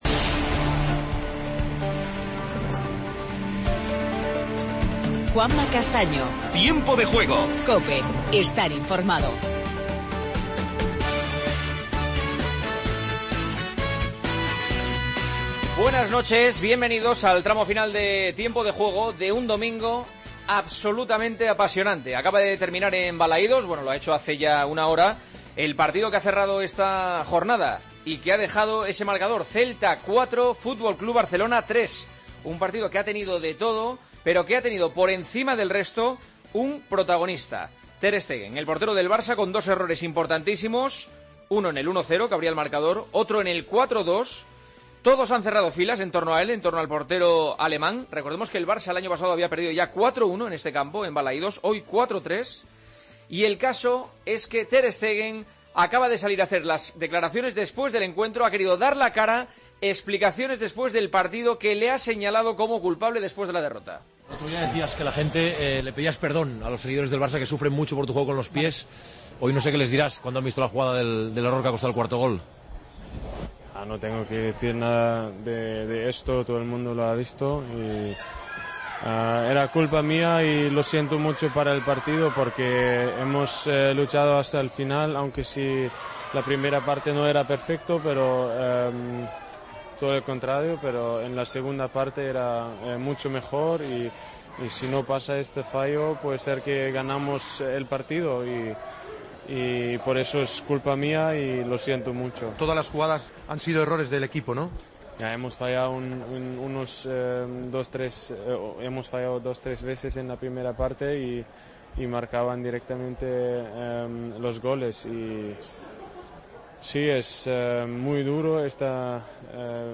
Escuchamos a Piqué, Luis Enrique y Ter Stegen tras la derrota del Barcelona en Vigo. Entrevista a Iago Aspas, jugador del Celta.